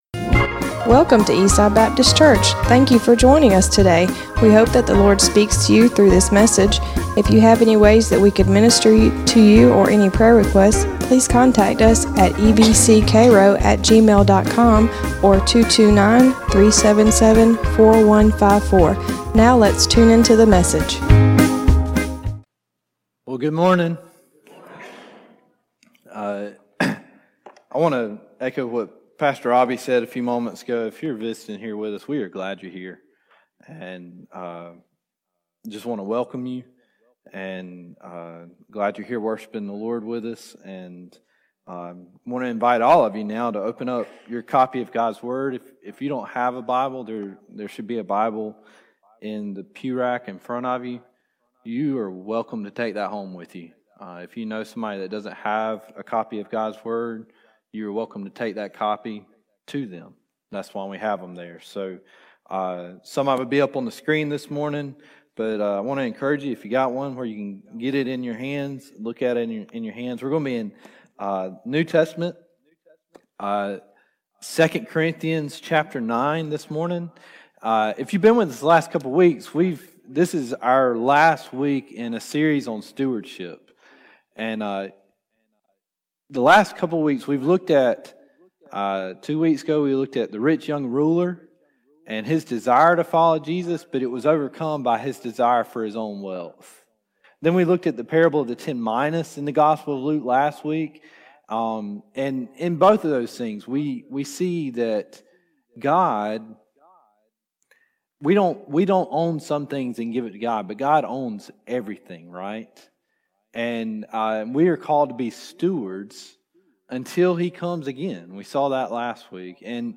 Sermons | Eastside Baptist Church
Guest Speaker